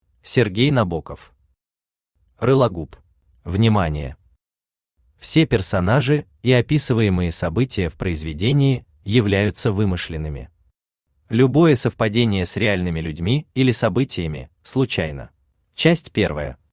Aудиокнига Рылогуб Автор Сергей Набоков Читает аудиокнигу Авточтец ЛитРес. Прослушать и бесплатно скачать фрагмент аудиокниги